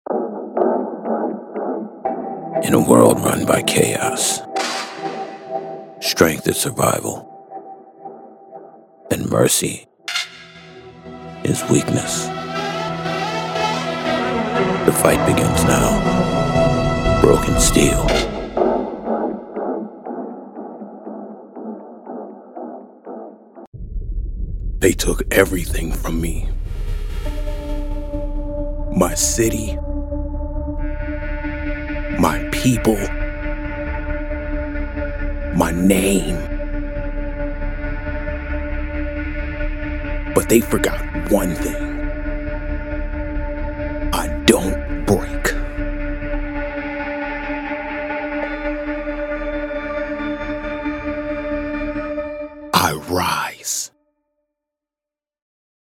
Style in every syllable. Depth in tone.
Video Game Demo